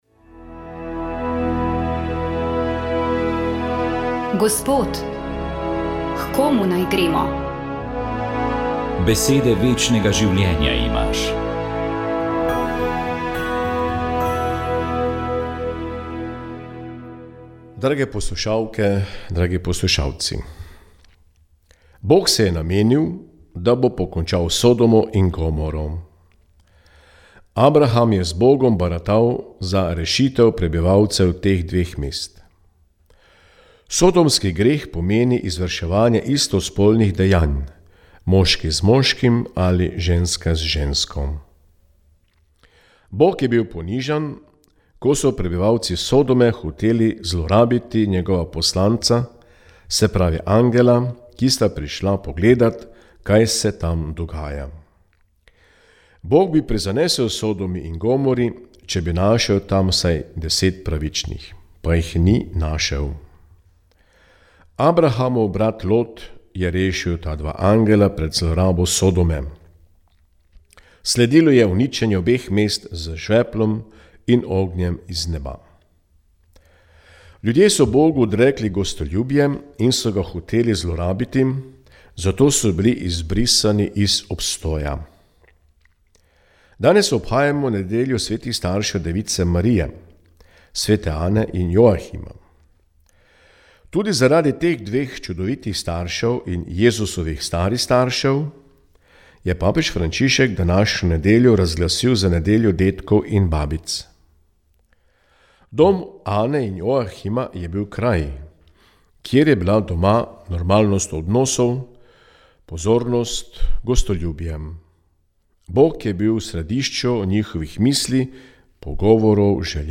Duhovni nagovor je pripravil nadškof Marjan Turnšek.